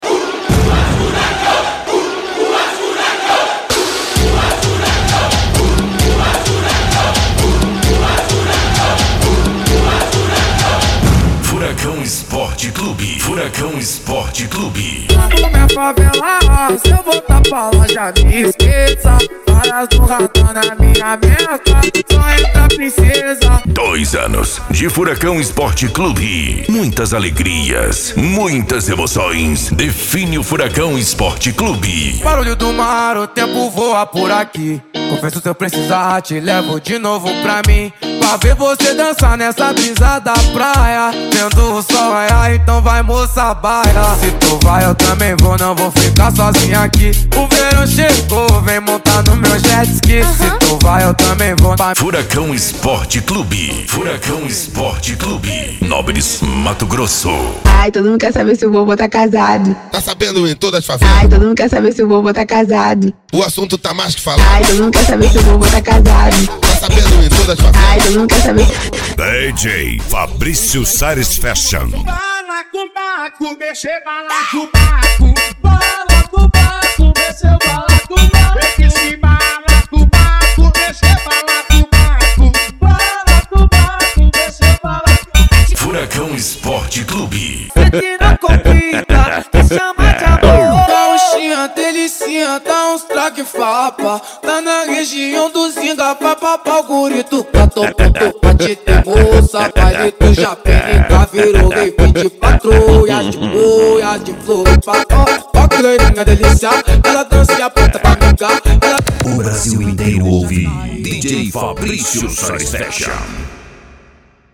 Funk
SERTANEJO
Sertanejo Raiz